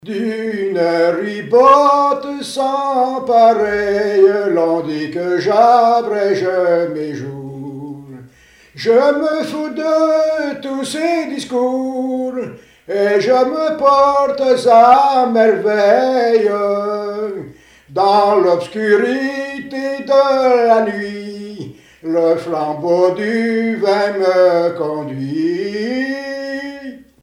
Mémoires et Patrimoines vivants - RaddO est une base de données d'archives iconographiques et sonores.
circonstance : bachique
Pièce musicale inédite